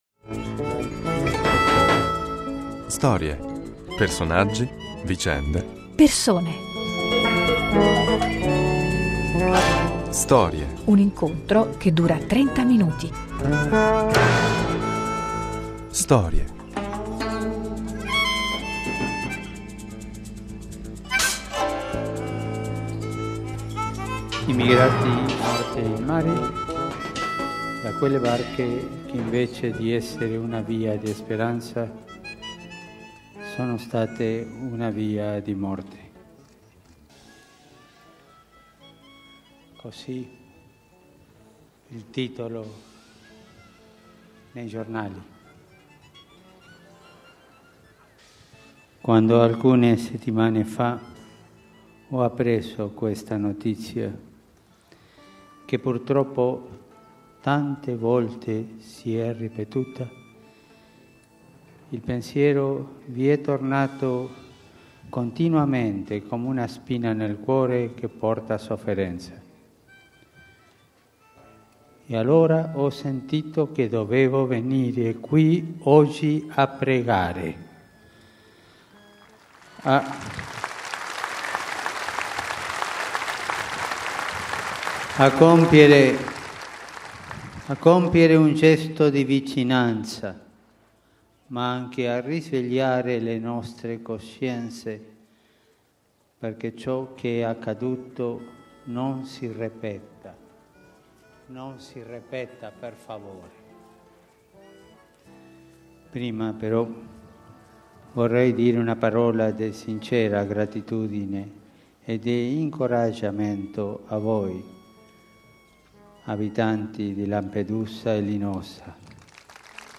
All'inizio della Settimana Santa, la croce di Lampedusa, che ha ricevuto la benedizione del papa, nercoledi scorso in udienza generale, racconta la sua storia con le voci dei suoi sostenitori.